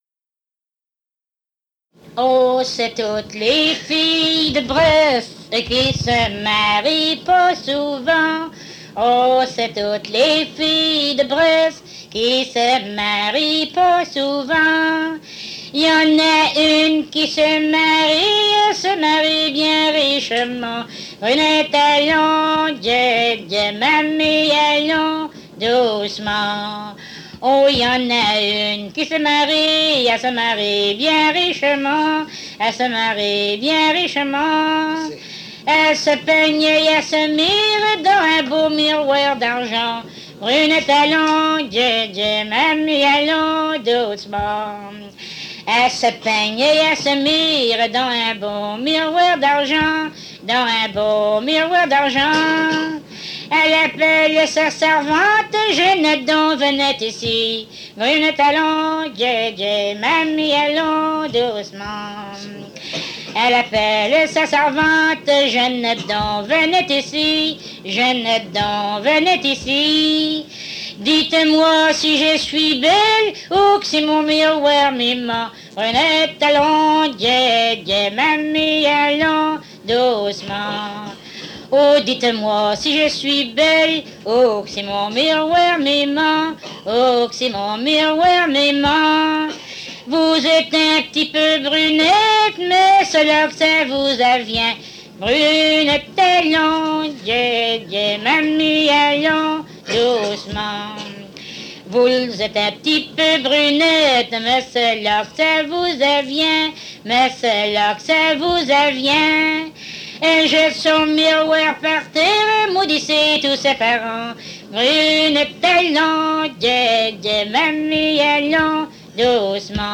Chanson
Emplacement Cap St-Georges